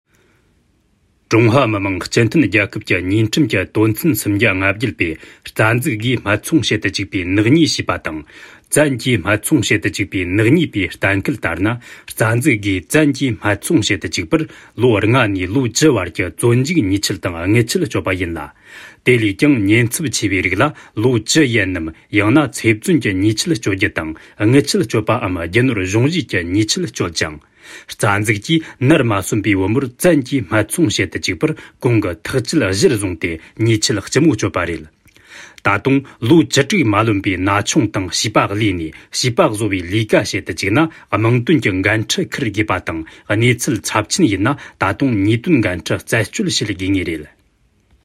安多藏语-成熟庄重